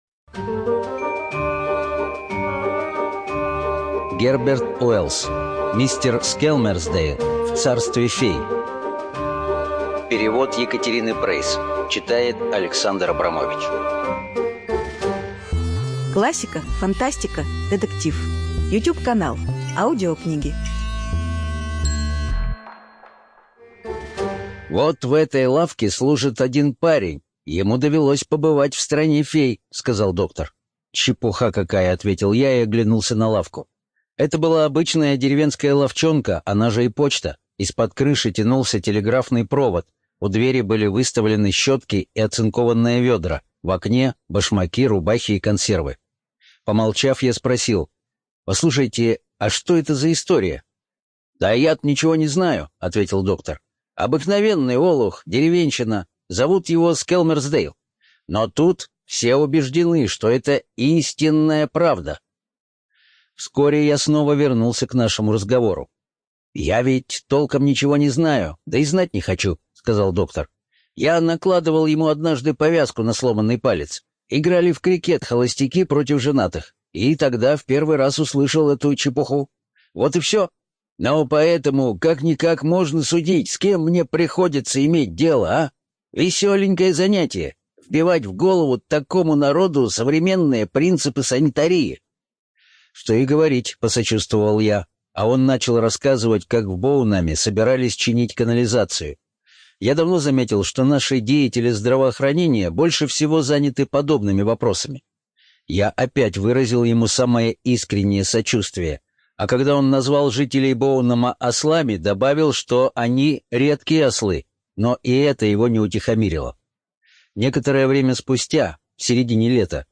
ЖанрСказки